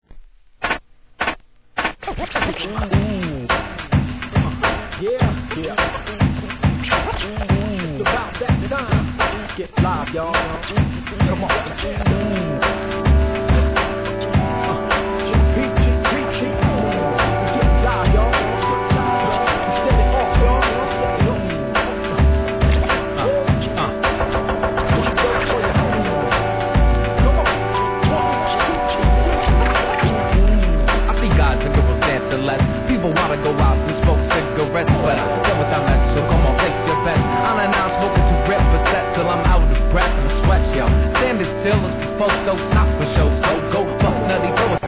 HIP HOP/R&B
軽快なJAZZ・ボサBEATの人気曲!!